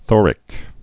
(thôrĭk, thŏr-)